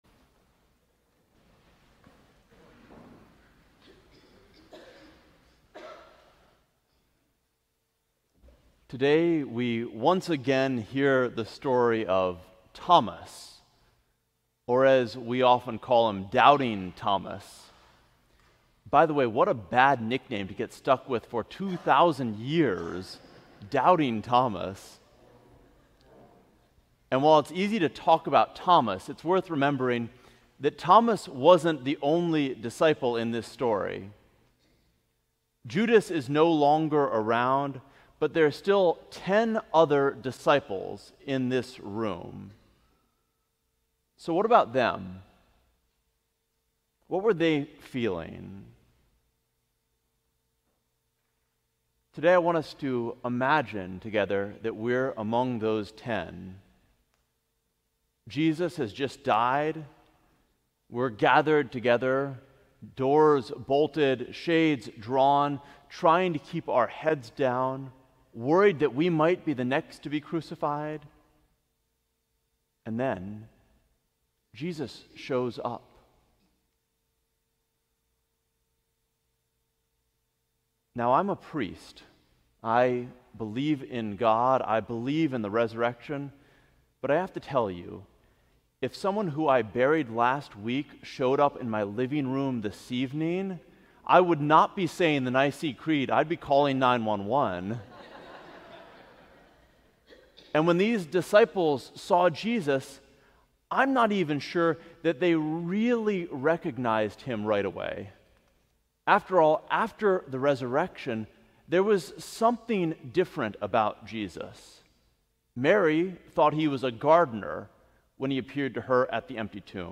Sermon: Faith Between Sundays